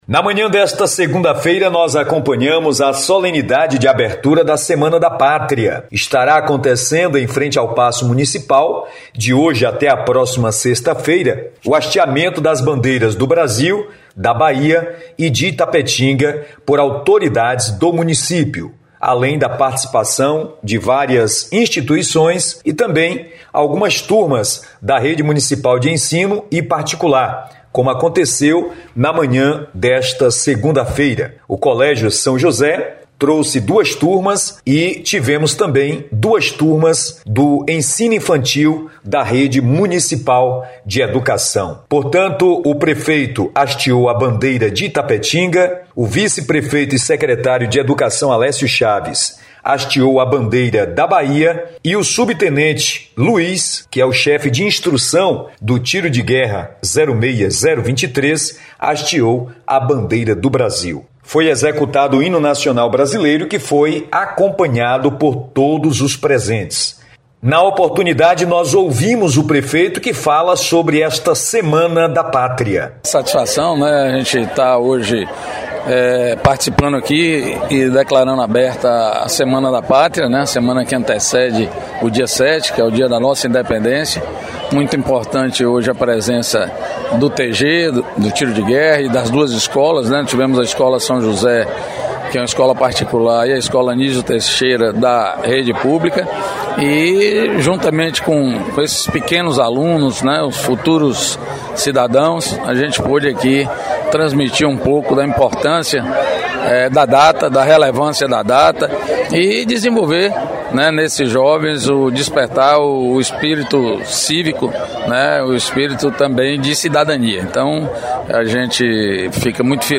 SEMANA DA PÁTRIA – Em Itapetinga, no sudoeste baiano, teve início na manhã desta segunda-feira, 01, a programação cívica com a cerimônia oficial de abertura, realizada no paço municipal, com a participação da Escola Anísio Teixeira (Rede Municipal de Ensino) e do Colégio São José (Rede Particular).